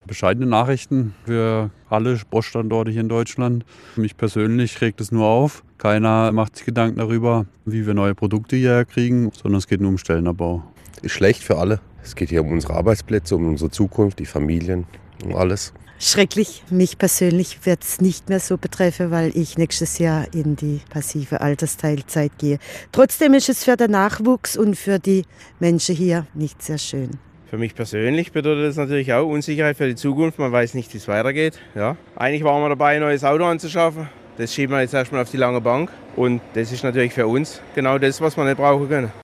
Bosch-Mitarbeiter beim Schichtwechsel am Freitagmorgen
Das sagen Bosch-Mitarbeiter in Bühl am Freitagmorgen zum geplanten Stellenabbau: